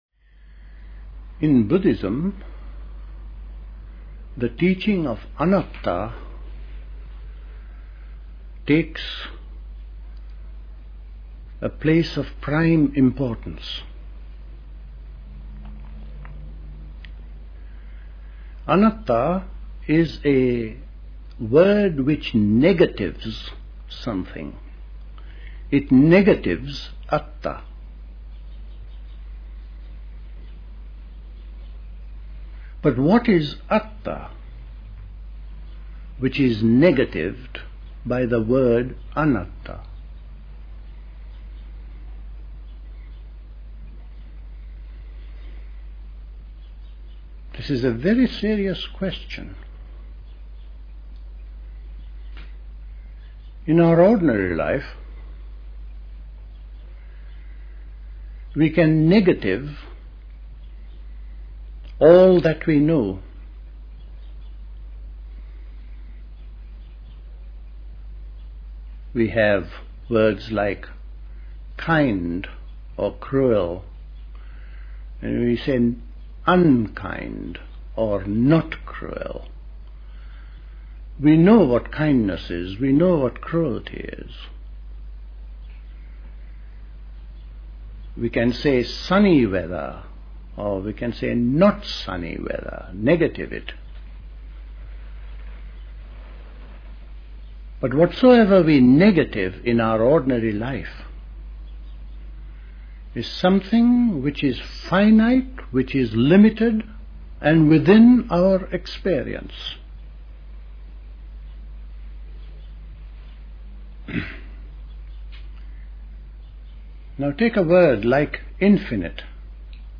A talk
at Dilkusha, Forest Hill, London on 21st June 1970